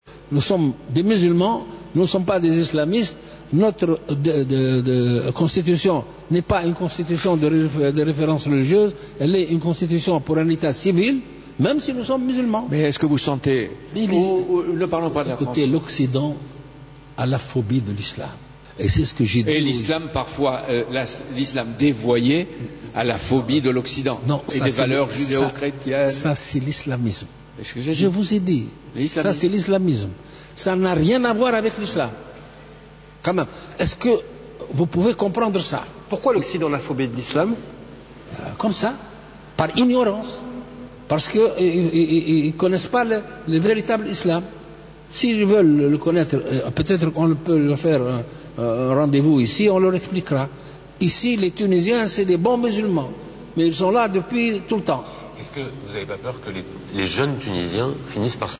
Dans une interview accordée, ce dimanche 22 mars 2015, à des médias français depuis le musée de Bardo, le président tunisien, Béji Caïed Essebsi a évoqué l'image de l’Islam telle qu'elle est perçue par les occidentaux.